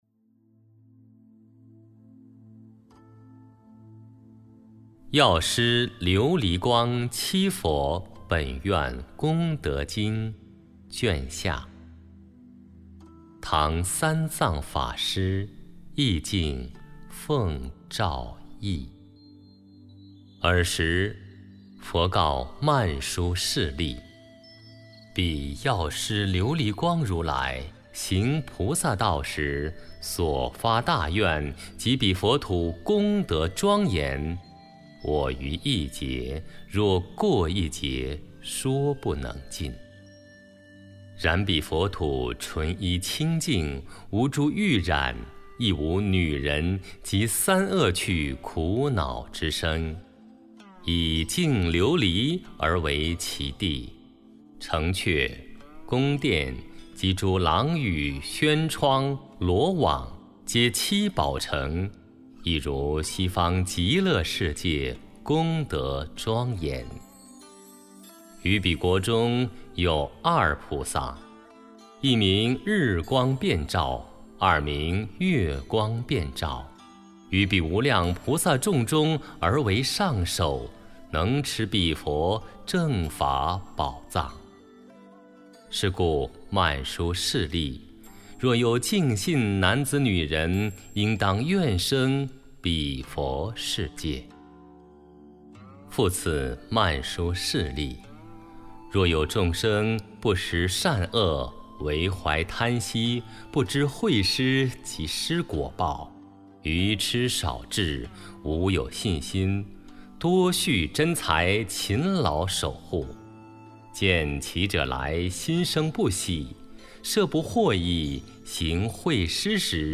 药师经读诵（下卷，本地音频） - 佛乐诵读